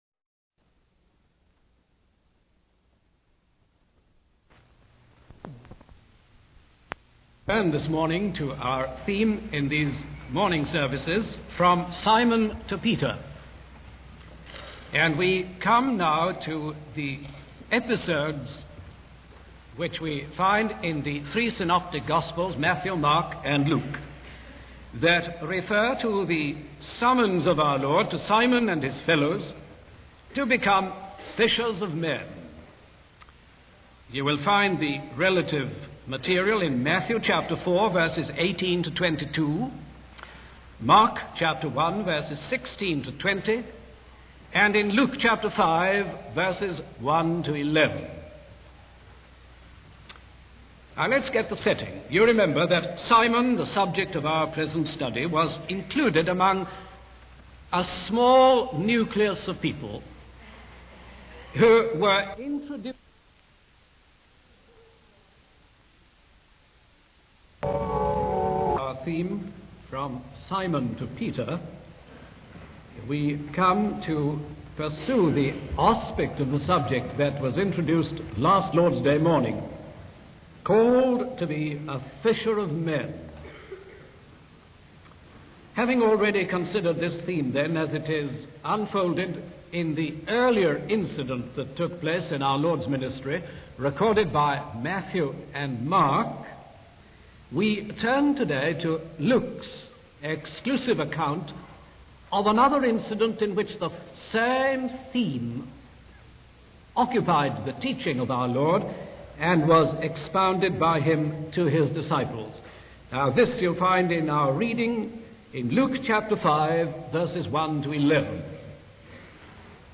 In this sermon, the preacher focuses on the theme of Simon being called to be a fisher of men. He refers to the incident recorded in Luke chapter 5, where Jesus teaches the people from Simon's boat and then instructs Simon to do what he tells him. The preacher emphasizes that as fallen humans, we often struggle to do what God tells us to do.